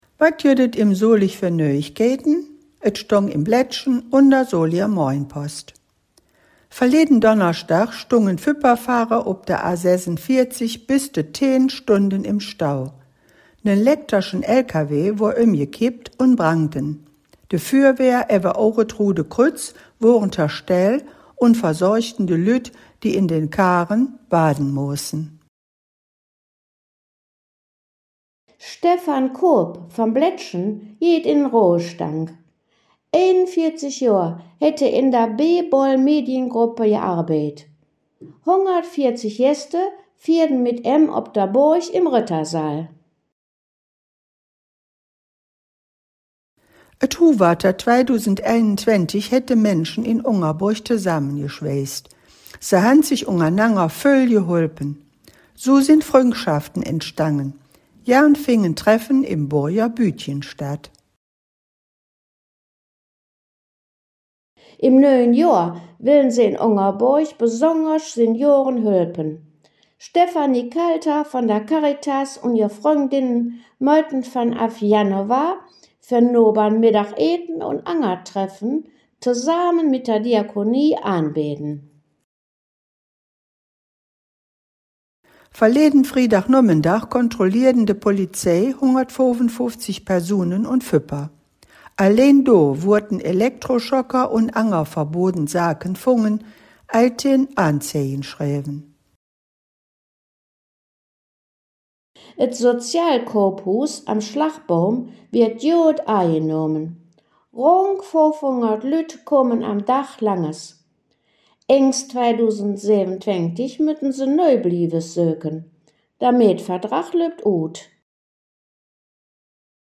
In dieser Folge der „Nöüegkeïten op Soliger Platt“ blicken „de Hangkgeschmedden“ in Solinger Platt auf die Nachrichten vom 13. bis zum 18. Dezember 2025 zurück.
Nöüegkeïten op Soliger Platt - Nachrichten in Solinger Platt